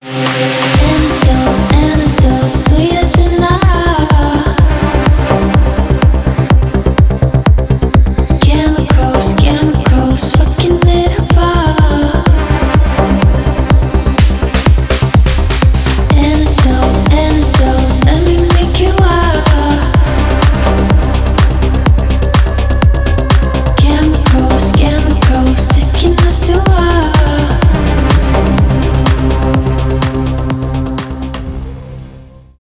Поп Музыка
клубные
громкие